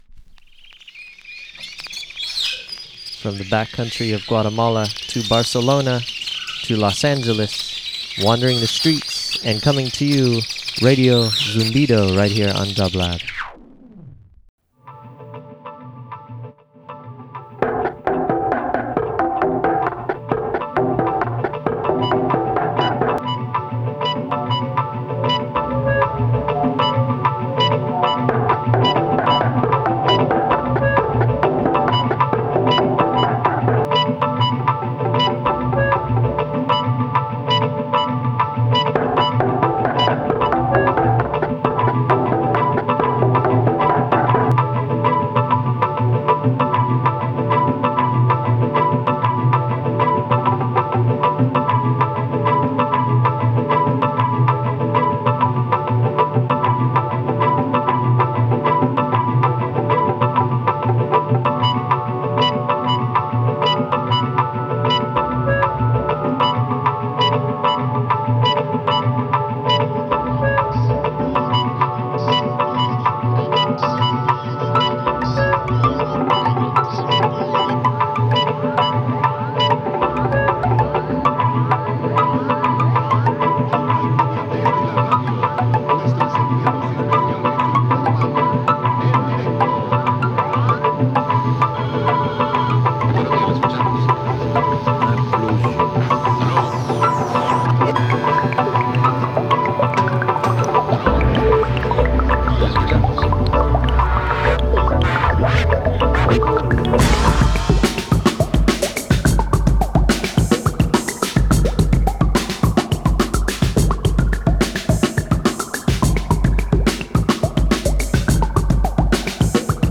Alternative Dance Downtempo Electronic Reggae/Dub